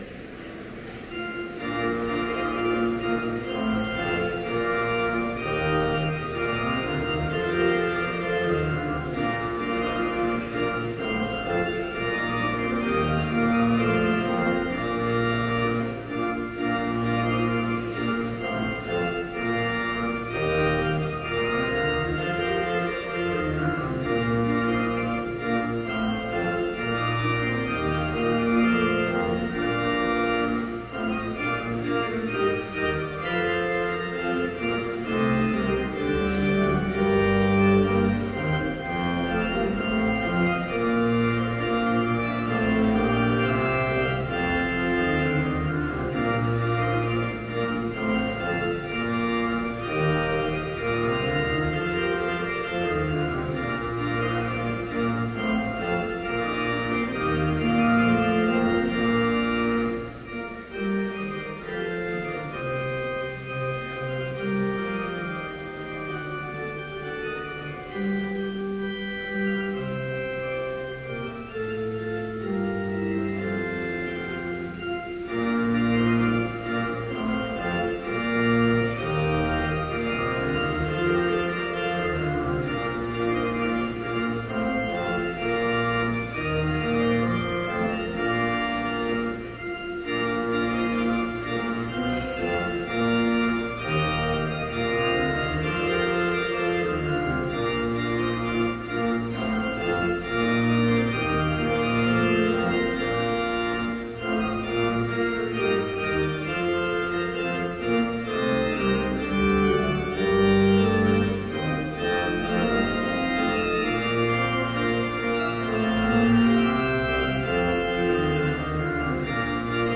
Chapel worship service in BLC's Trinity Chapel
Complete service audio for Chapel - March 28, 2015